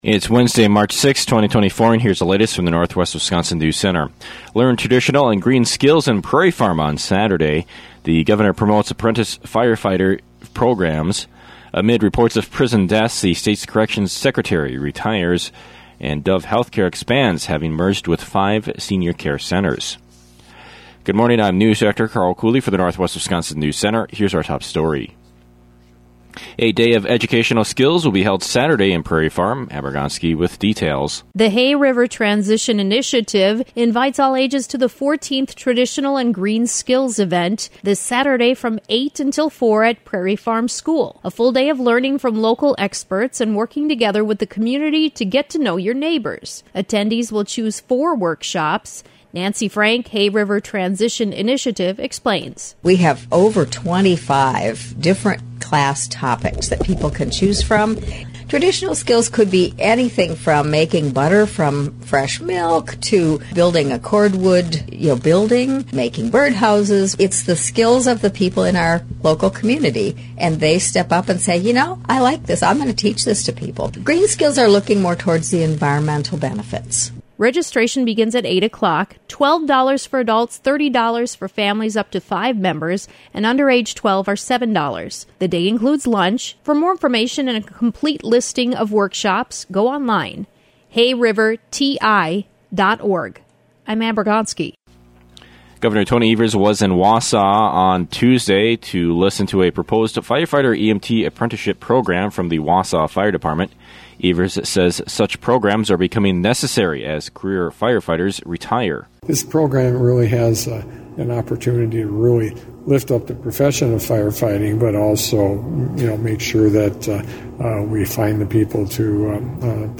This on today’s local newscast.